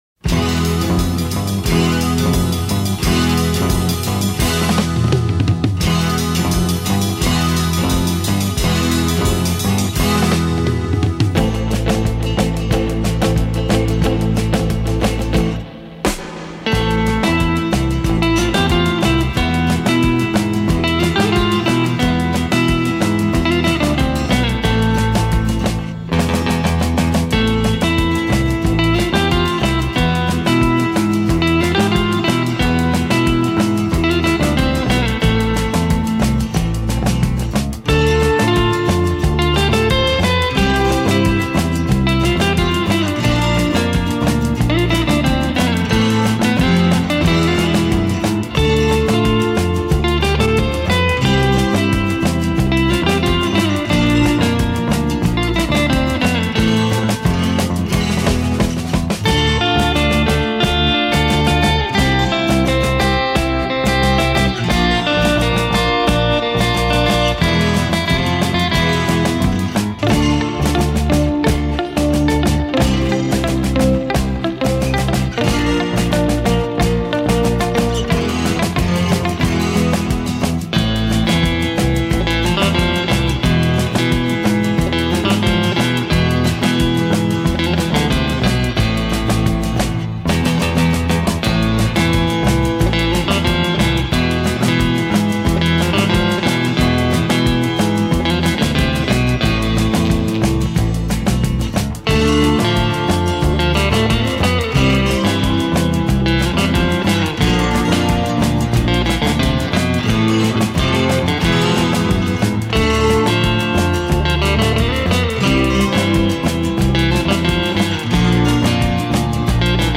замечательный инстументал!